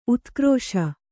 ♪ utkrōśa